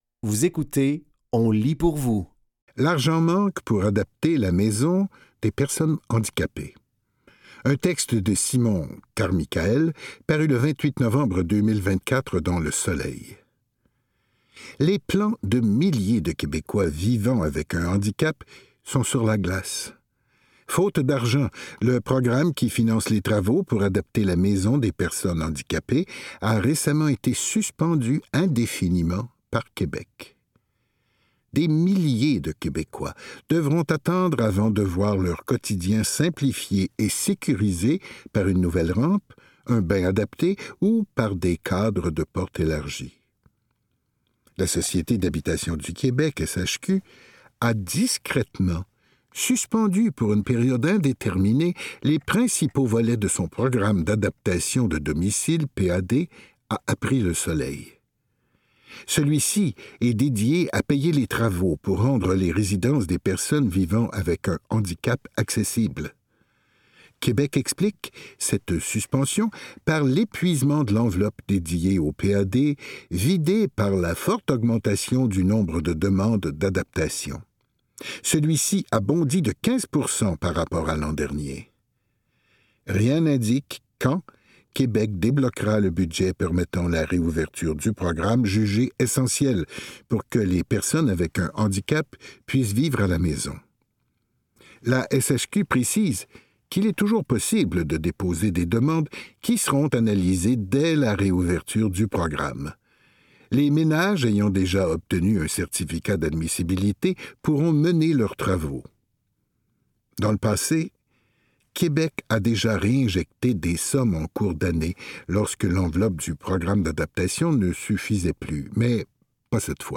Dans cet épisode de On lit pour vous, nous vous offrons une sélection de textes tirés des médias suivants : Le Soleil, La Presse, ICI Île-du-Prince-Édouard et Le Journal de Montréal.